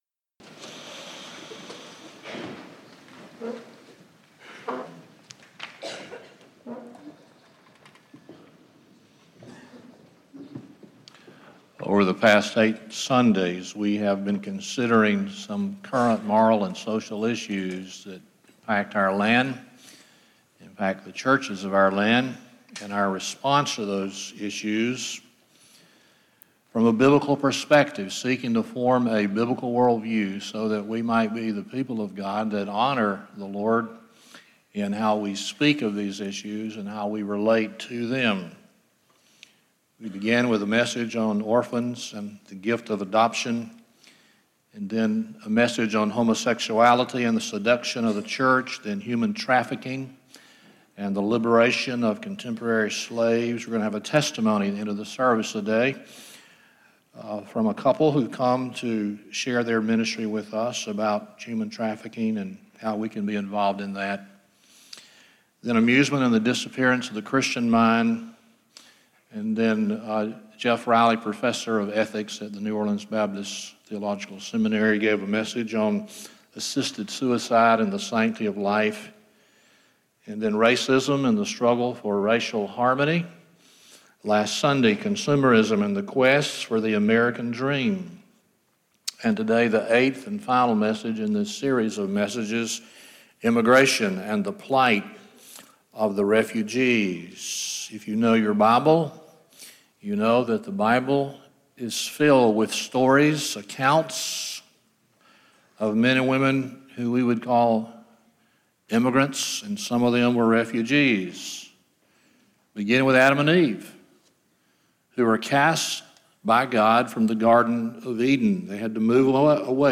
Service Type: Sunday Morning Topics: Immigration , Refugees